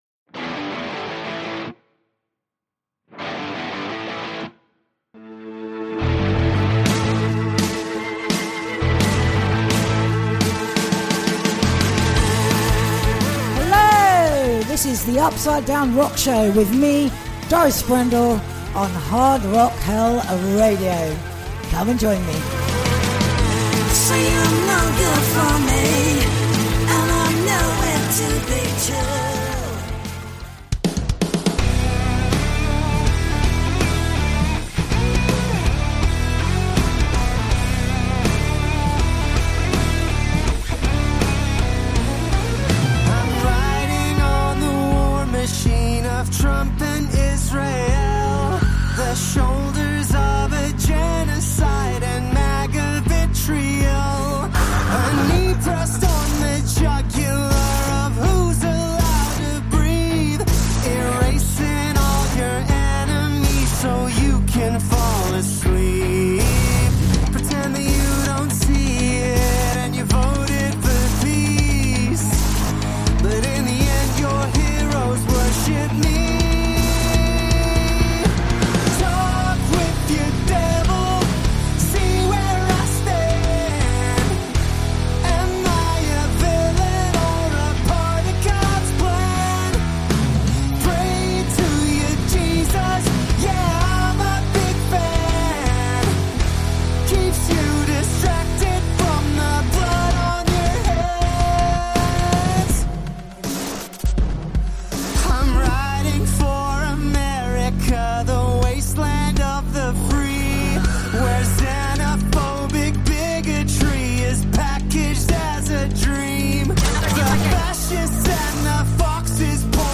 The 9th part of the Marketing Workshop for musicians. This week’s episode is called ‘Now What?!’ and covers a whole bunch of marketing bits and bobs that haven’t been mentioned. Plus a fabulous selection of new rock releases